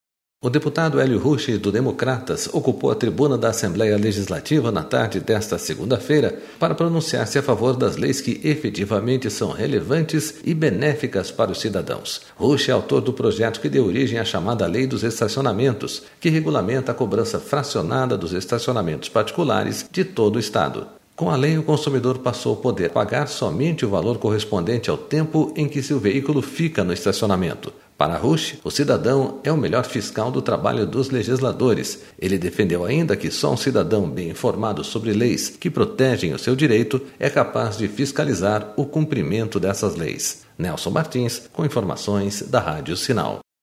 O deputado Elio Rusch, do Democratas, ocupou a tribuna da Assembleia Legislativa na tarde desta segunda-feira para pronunciar-se a favor das leis que efetivamente são relevantes e benéficas para os cidadãos.//